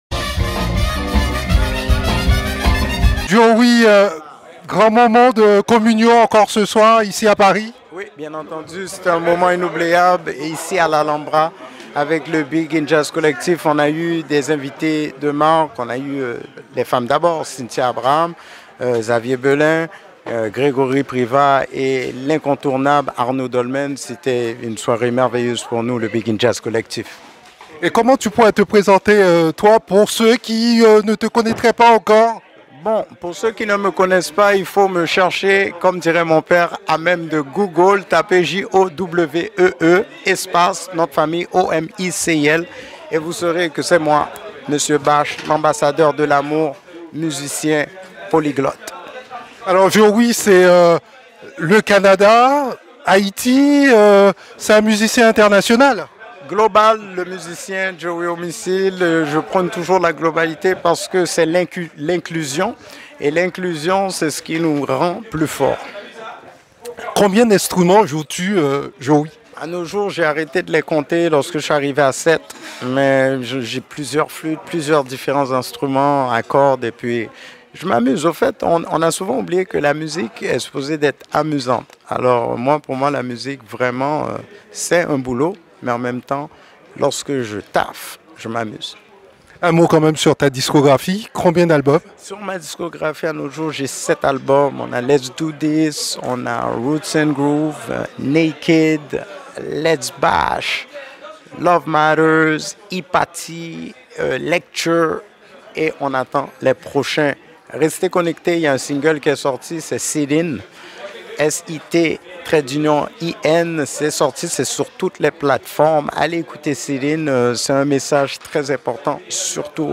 Rencontre à Paris